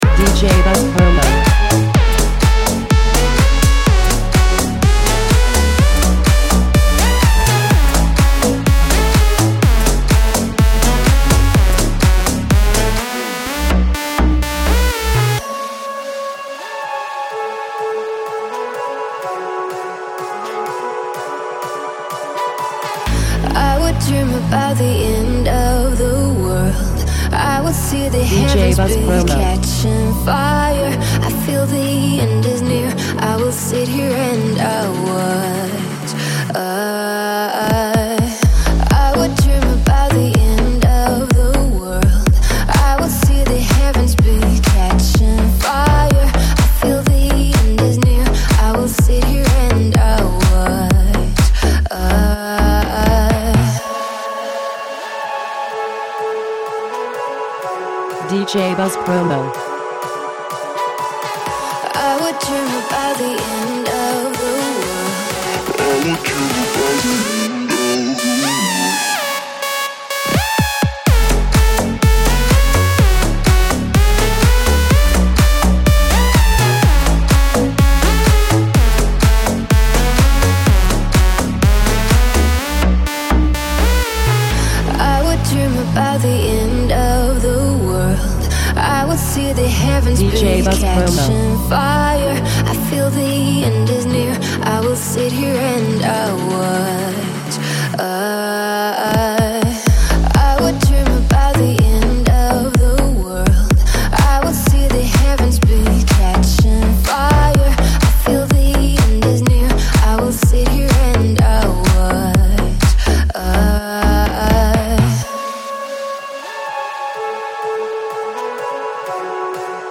They offer an energetic track with a melancholy theme.